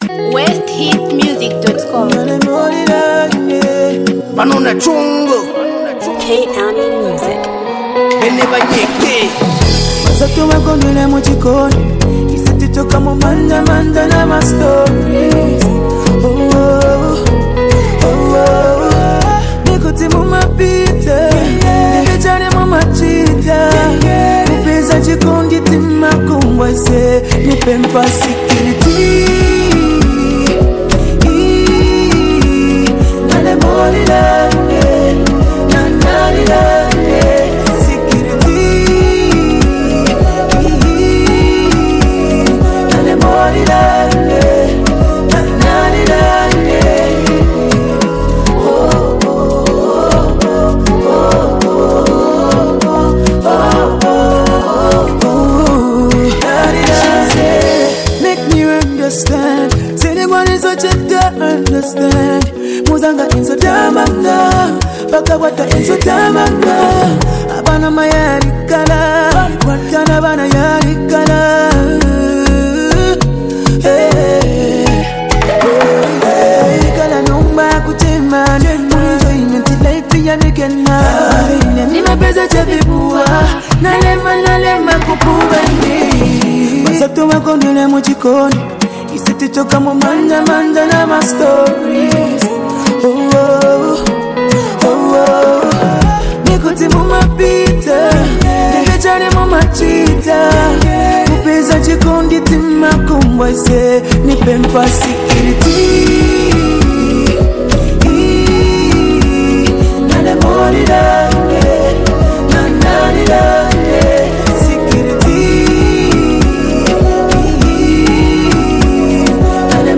catchy track